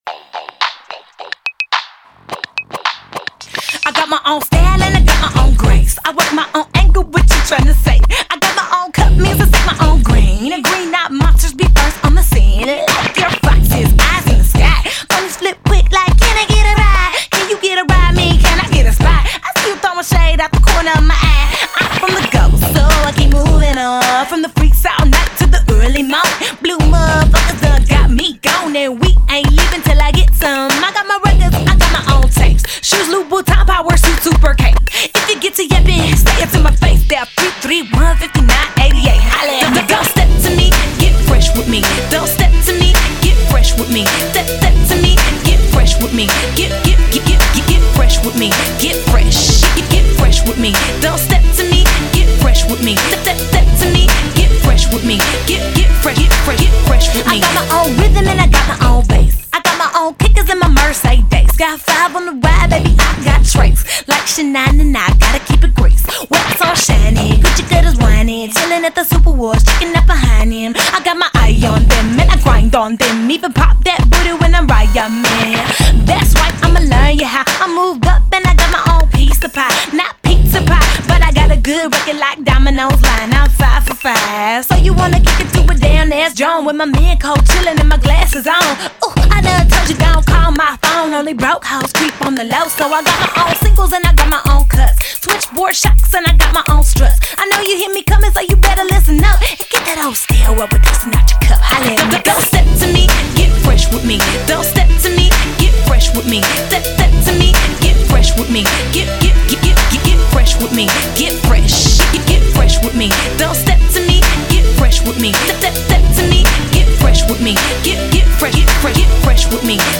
has just as much attitude and style as her first hit.